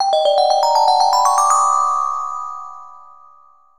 chimes.wav